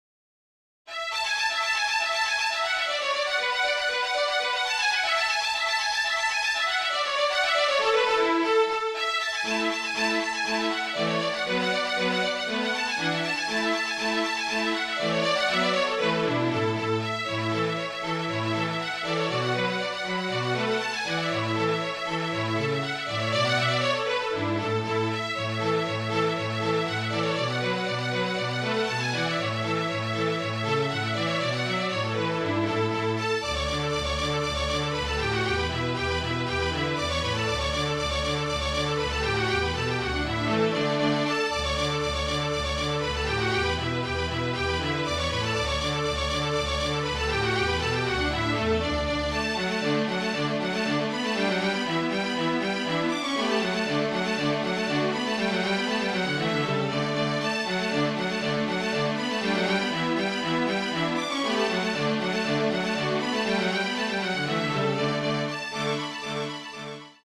MIDI
(Flute Trio Version)